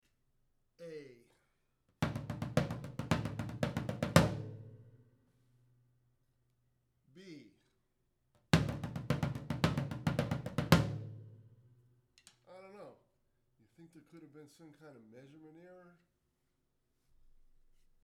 Did I mention I use Pinstripes exclusively? so those two samples with pinstripes, thanks.
I can tell a subtle difference. A is looser, rounder, fuller, and sounds like the recording on Bob's Socks album on the third song, My Baby Eats Cheese.
BTW, those pins sound GREAT!!!!